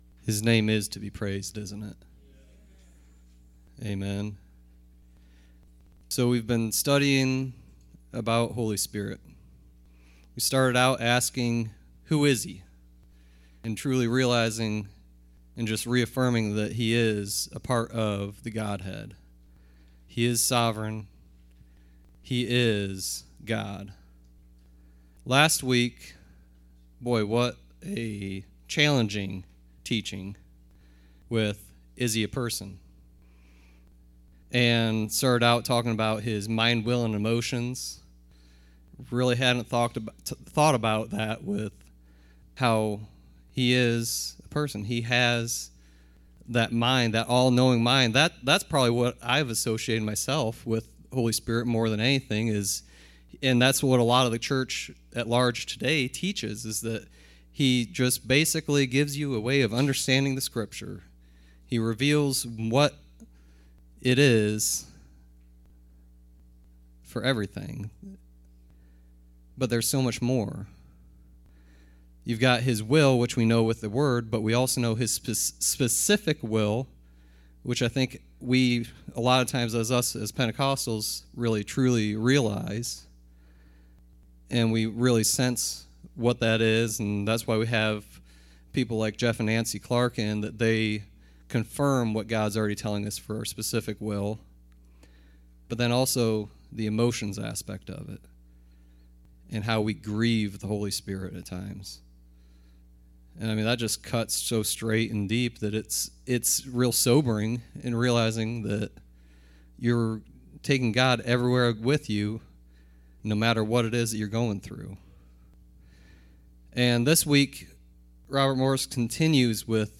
Service Type: Wednesday Teaching